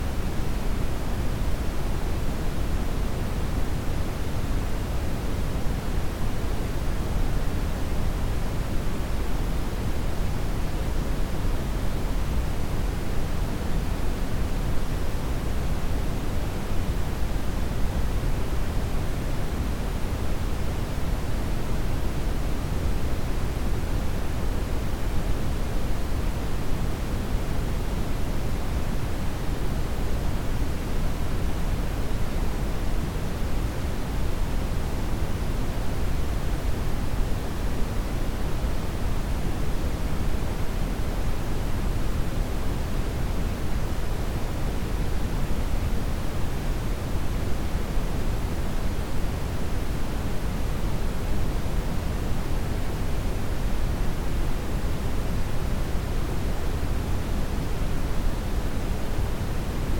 White, Pink and Brown noise, digitally crafted to imitate sounds from across the Land of the Long White Cloud; Aotearoa New Zealand.
Track I - Brown Noise - The Sound of Huka Falls (60 secs)
landofthelongwhitenoise_hukafalls.mp3